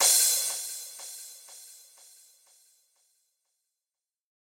ORG Crash FX C7.wav